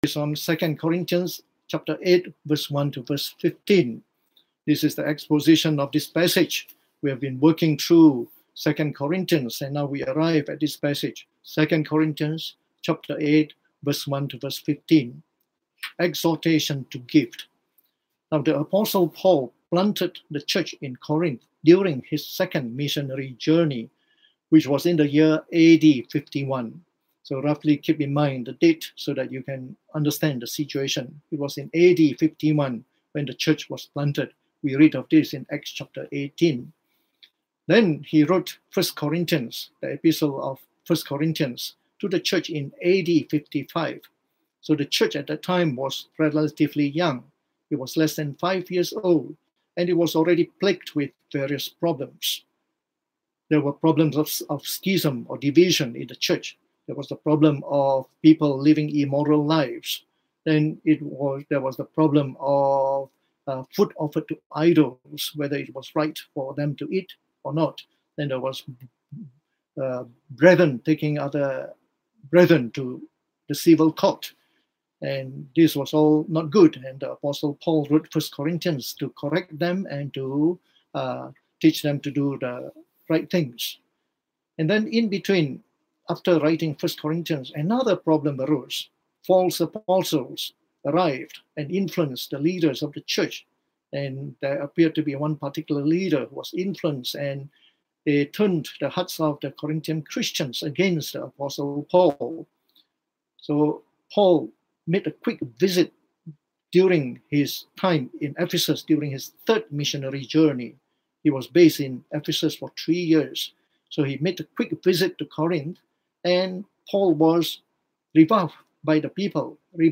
From 2Corinthians delivered online in the Evening Service